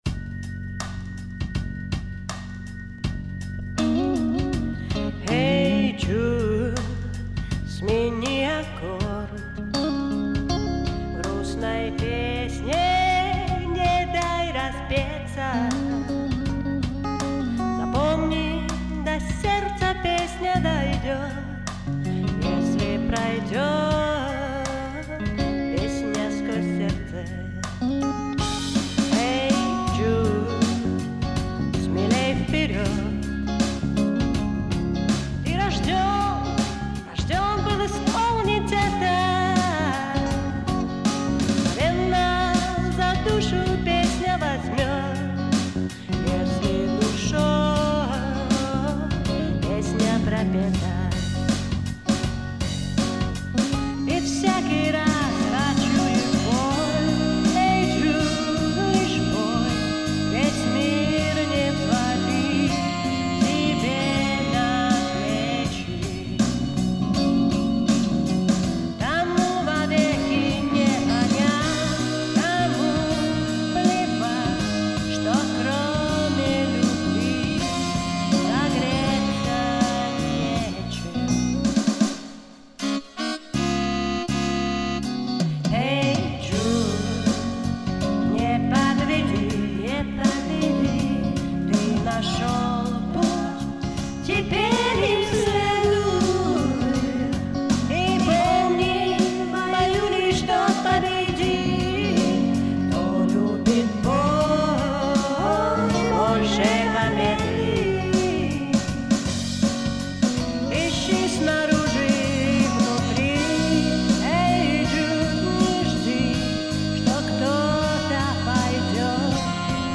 Там даже голос поуверенней звучит...